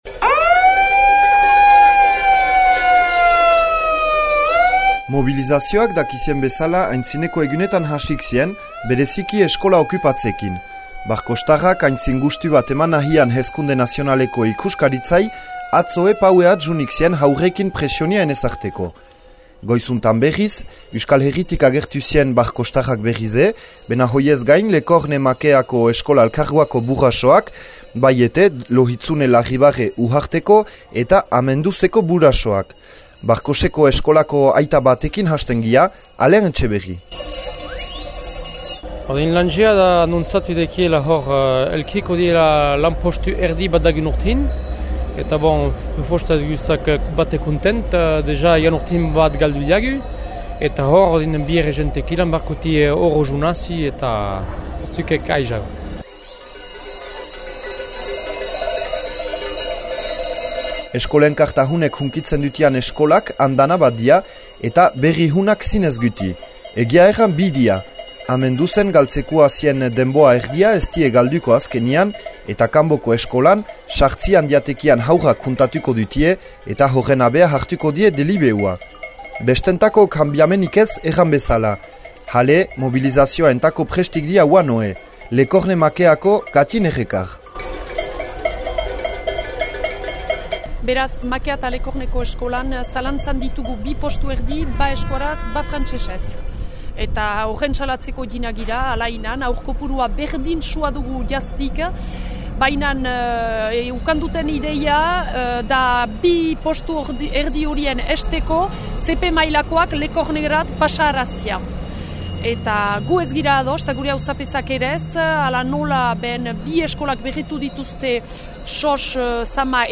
Entzün Pauen egin dügün erreportajea :